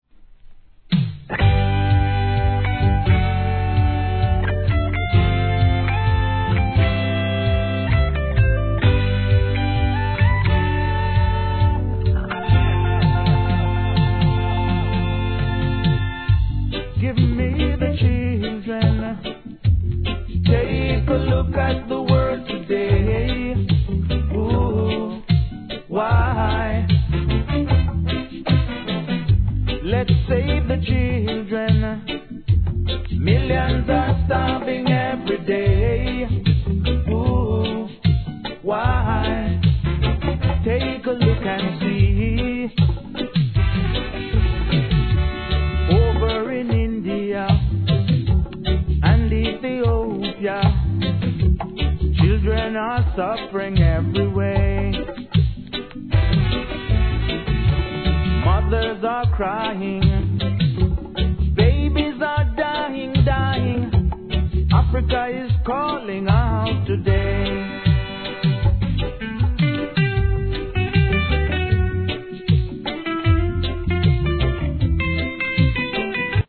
REGGAE
ノリノリなディスコナンバー!!と思わせる様なイントロから一転、心地よい裏打ちで聴かせる好レゲエ!!